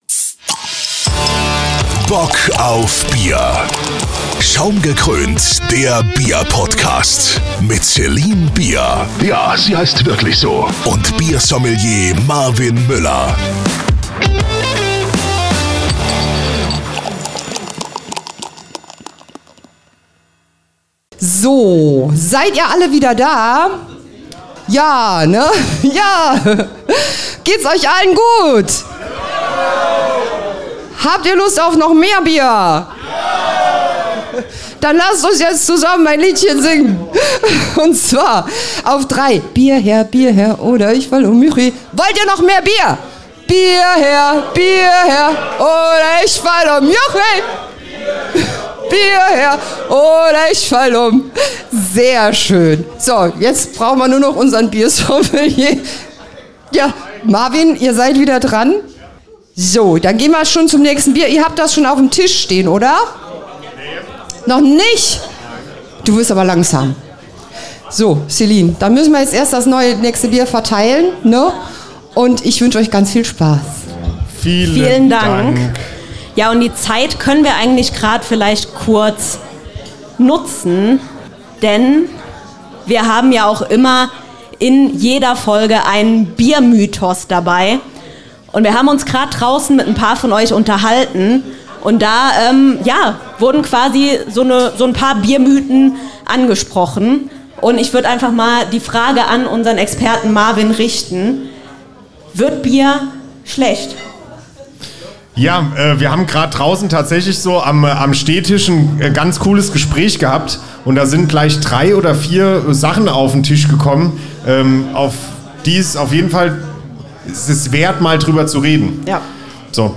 Munter und feucht-fröhlich geht es heute weiter mit Teil zwei unserer ersten großen live aufgezeichneten Folge im Rahmen des Unner Uns Events.